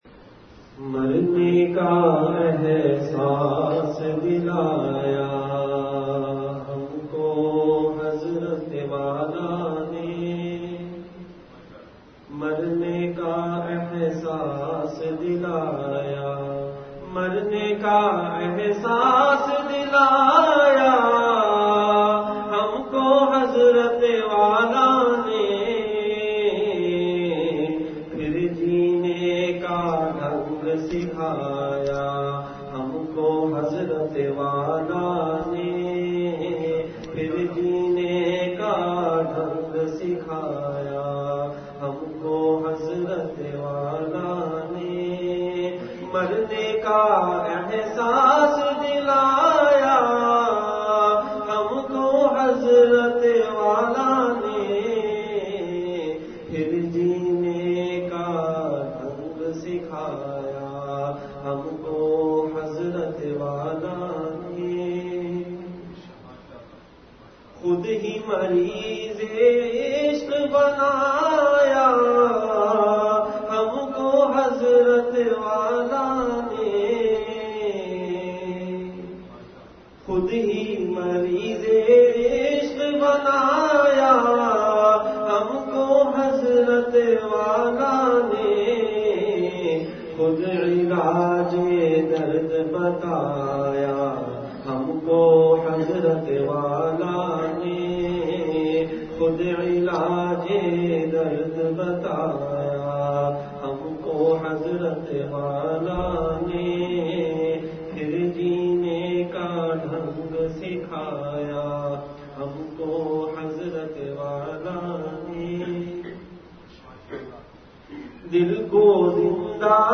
Majlis-e-Zikr
Venue Home Event / Time After Magrib Prayer